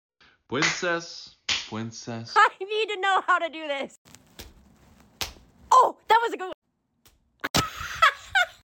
make loud claps for no good reason